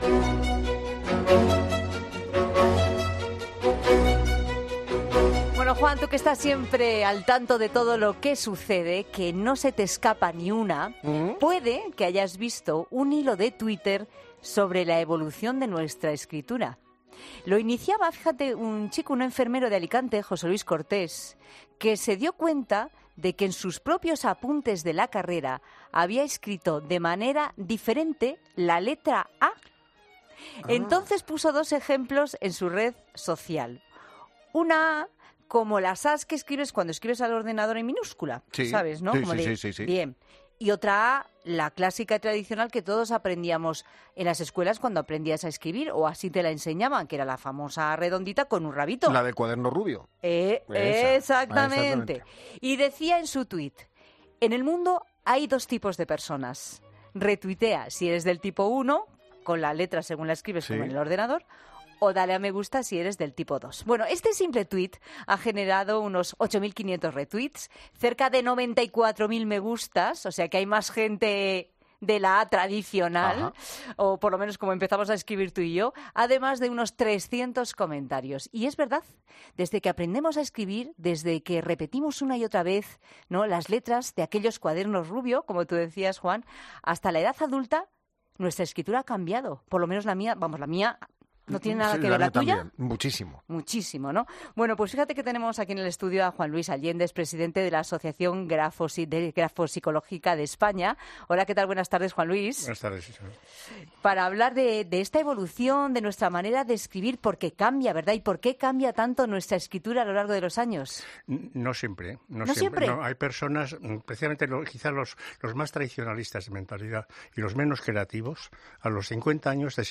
En La Tarde de COPE hablamos con